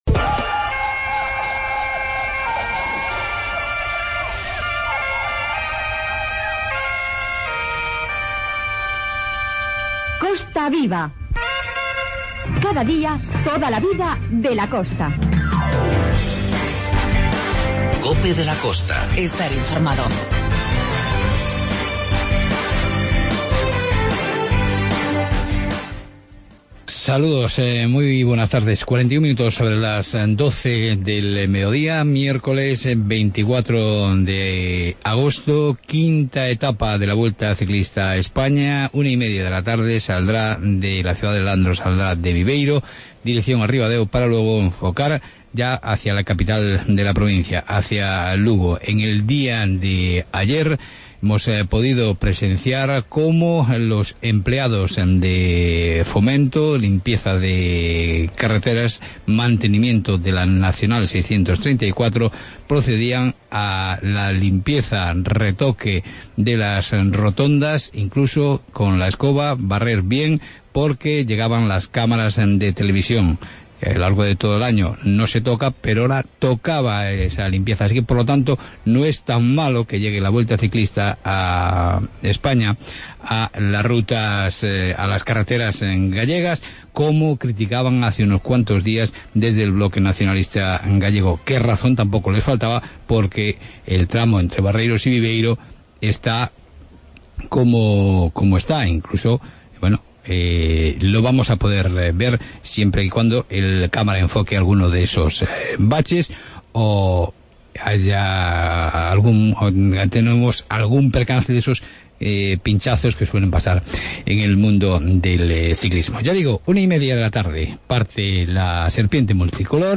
Informativo "Costa Viva"